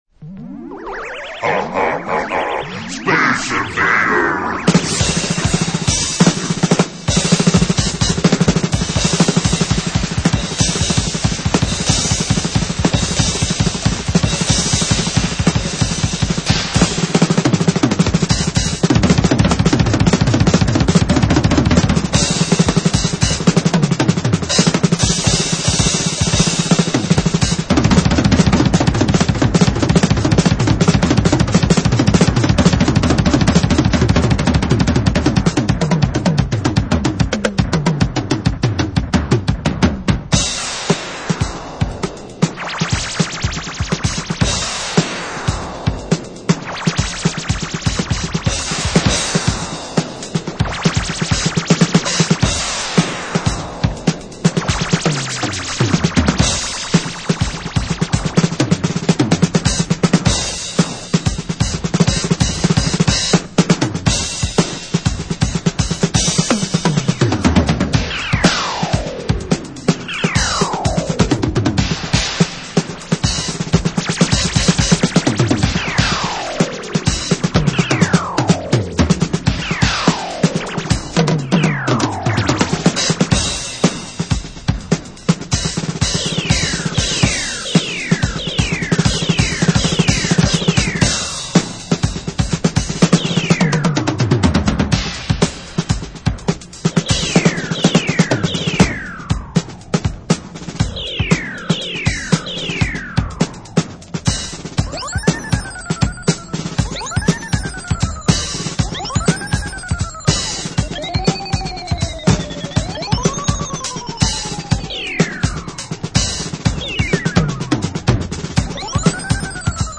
Listen to a snippet of the 4 minute drum crazy track